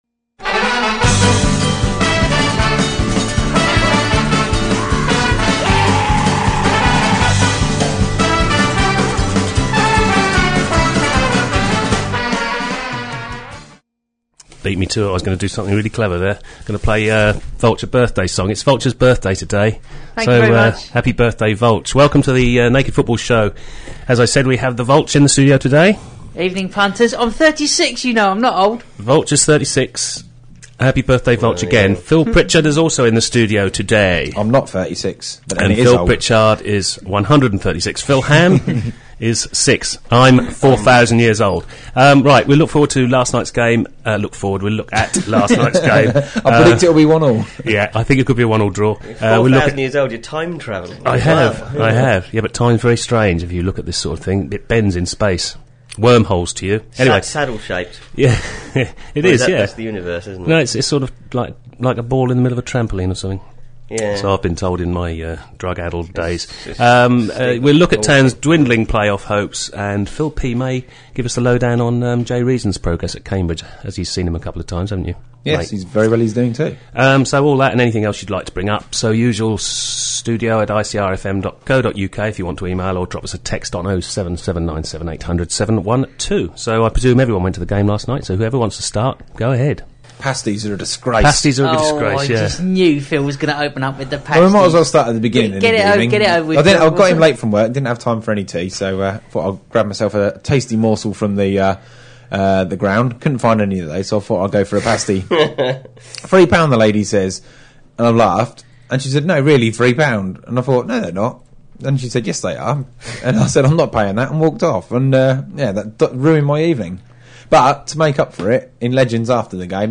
The programme goes out live at 6pm every Wednesday on Ipswich Community Radio at 105.7FM if in the Ipswich area or online if not.